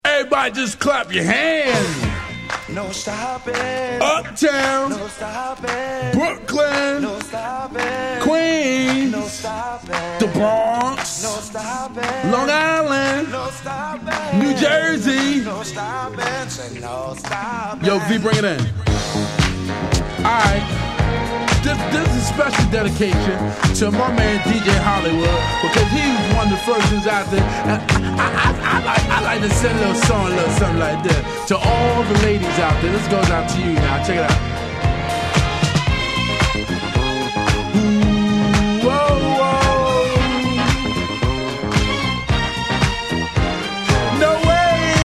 問答無用の90's Hip Hop Classic !!!